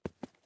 ES_Footsteps Concrete 8.wav